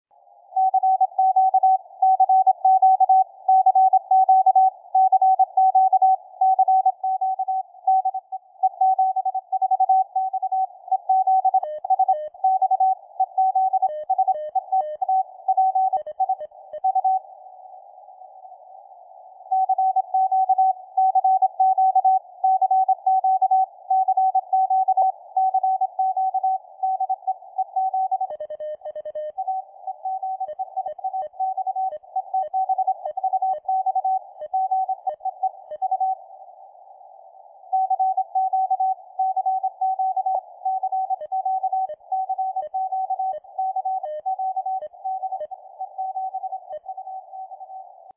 Поэтому выбрал слабенькую станцию и поизгалялся прямо на ее сигнале.
Впечатления от QSK ПФР полностью соответствуют тому, что я и говорил - кажется, что прием не прерывается, а просто слышен свой звуковой генератор на фоне сигнала из эфира!